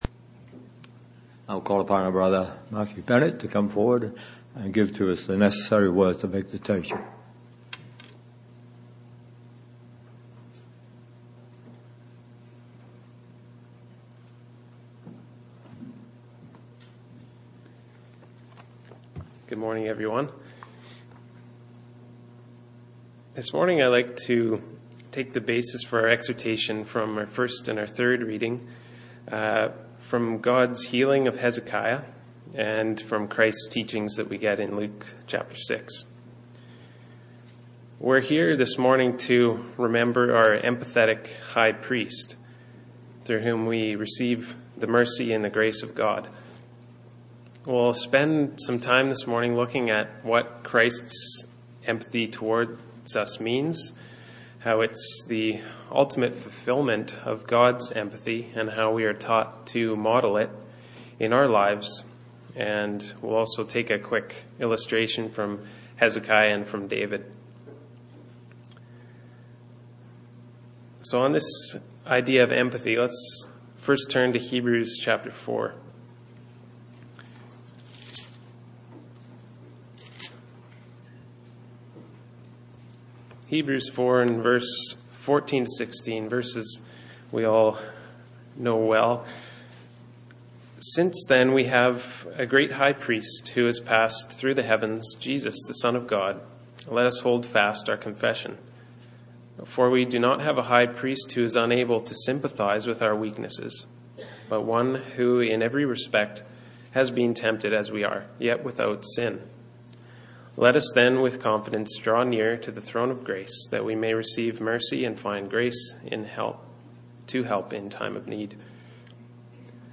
Exhortation 09-14-14